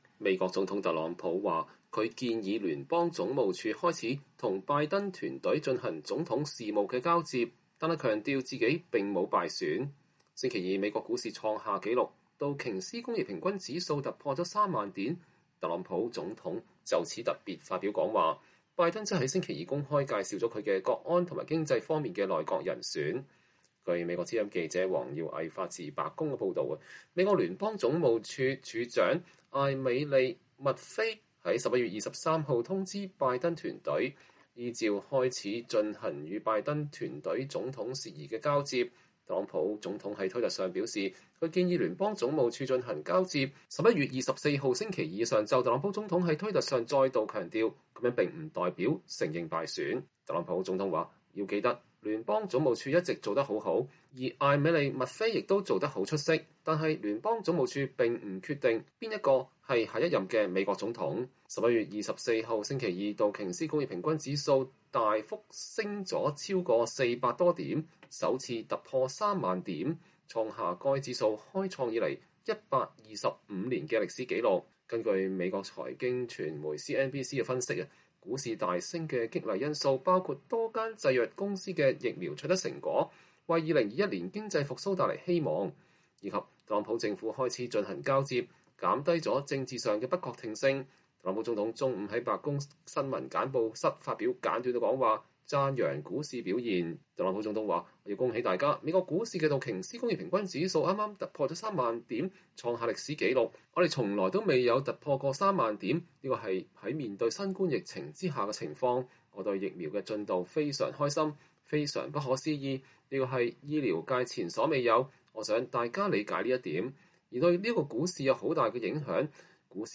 特朗普總統中午在白宮新聞簡報室發表簡短講話，讚揚股市的表現：
拜登在星期二下午一點召開新聞發布會，介紹了他規劃的國安與經濟團隊，包括將提名前副國務卿布林肯(Antony Blinken)出任國務卿，海恩斯(Avril Haines)為首位女性國家情報總監等。